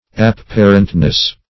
\Ap*par"ent*ness\